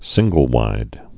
(sĭnggəl-wīd)